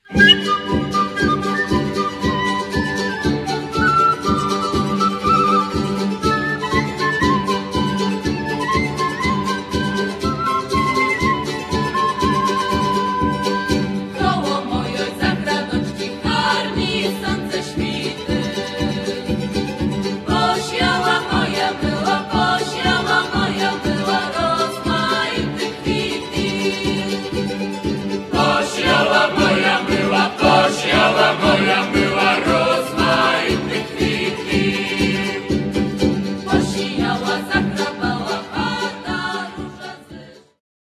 piosenka łemkowska a Lemkovyna/Lemkivshchyna song
akordeon, drumle, ¶piew accordion, Jew's harps, vocals
gitara, skrzypce, ¶piew guitar, violin, vocals
instrumenty perkusyjne percussion instruments